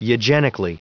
Prononciation du mot eugenically en anglais (fichier audio)
Prononciation du mot : eugenically